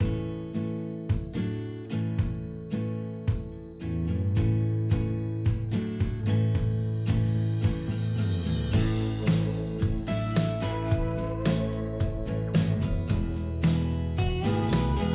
Music-On-Hold Options